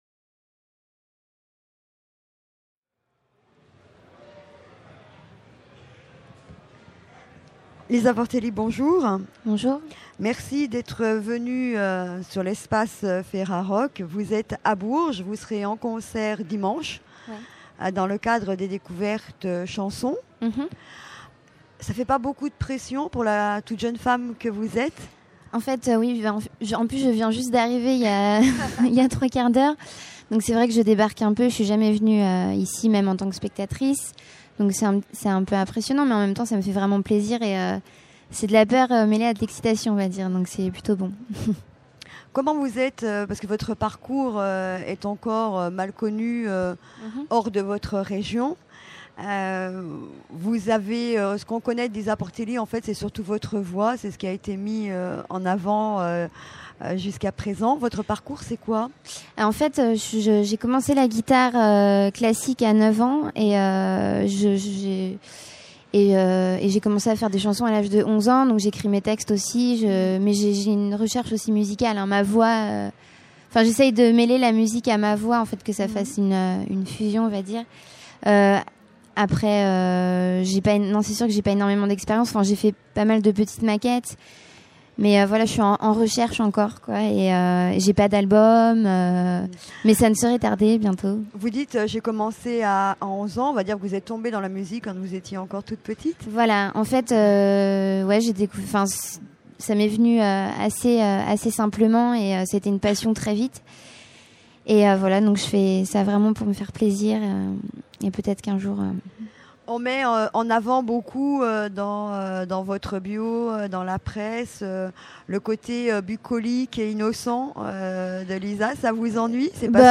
Festival du Printemps de Bourges 2006 : 40 Interviews à écouter !